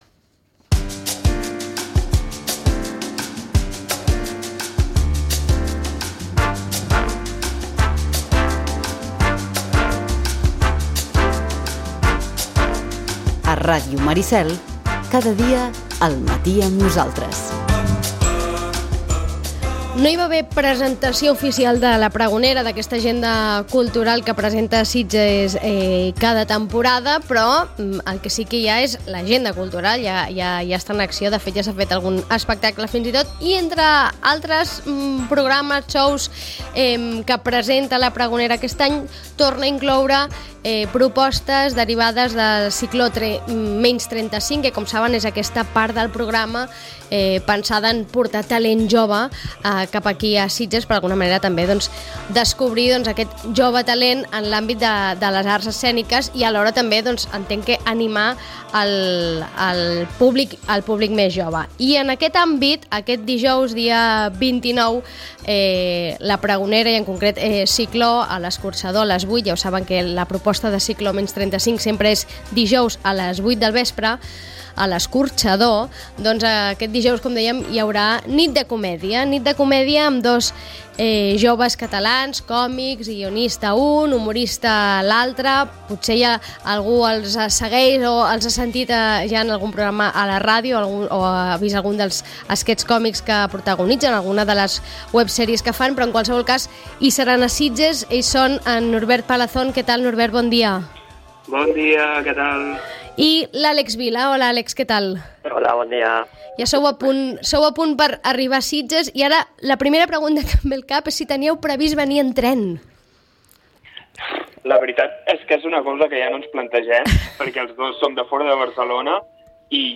Fem la prèvia amb aquests dos joves centrats en la comèdia de la que voldrien viure. L’espectacle obre la temporada del programa Cicló <35 creat i pensat en portar propostes culturals a Sitges protagonitzades per talent jove i pensades també per a un públic jove.